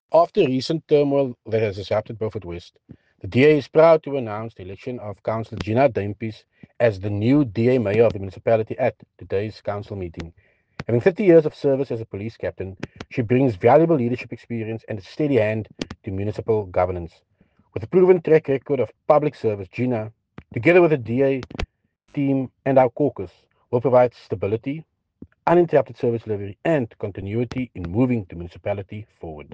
soundbite by Tertuis Simmers
Tertuis-Simmers-on-new-BFW-Mayor.mp3